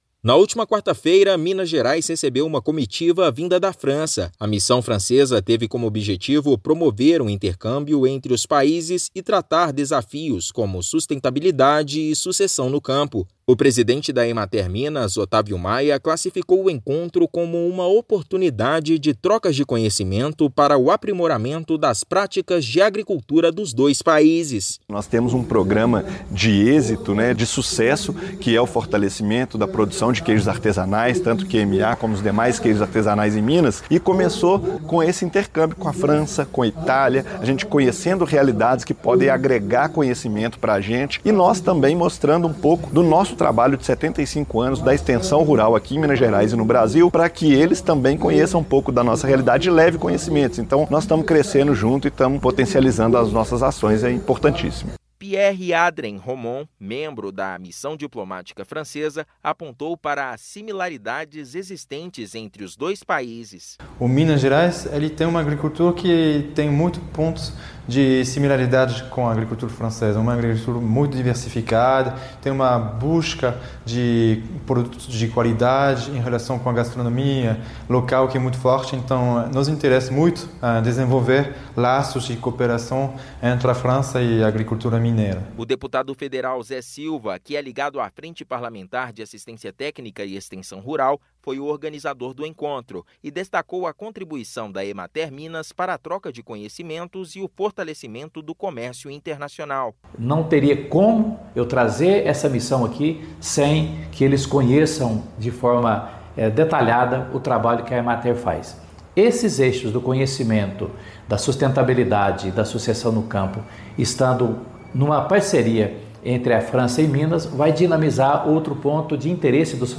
Troca de experiências foi fundamental para aprimoramento da agricultura em ambos os países. Ouça matéria de rádio.